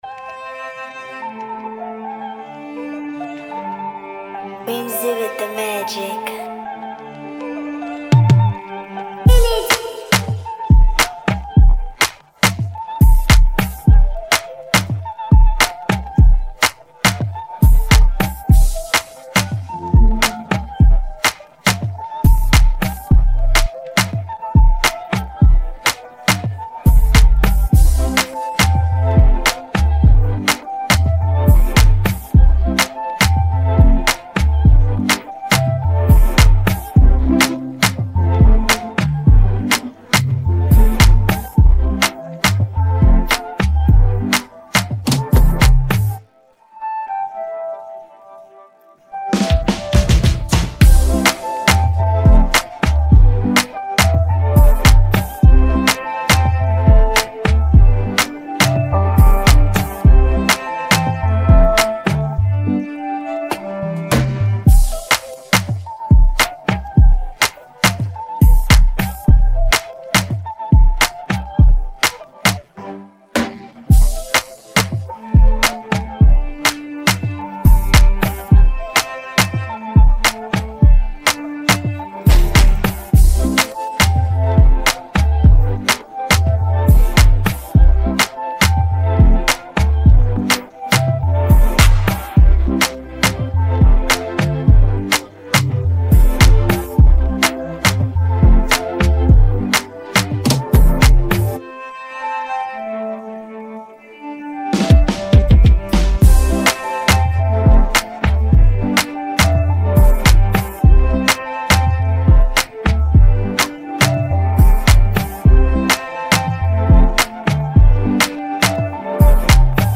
instrumental track
soulful vibes